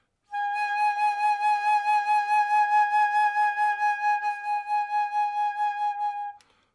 横笛：颤音C4 C7 " 横笛G6 颤音
描述：这是一个横笛在第六个八度演奏G调的乐器样本。这个音符的攻击和保持是Tenuto和Vibrato。使用的调谐频率（音乐会音高）是442，动态意图是中音。这个样本属于一个多样本包 乐队乐器。横笛乐器。AerophoneAerophone。木管乐器音符：G八度。6音乐会音高：442Hz动态：Mezzoforte攻击。攻击：Tenuto持续。颤音麦克风。ZoomH2N话筒设置。XY
标签： 长笛 多重采样 放大H2N 颤音 持续音 mezzoforte 克锐-6 木管乐器 管乐器 横向槽
声道立体声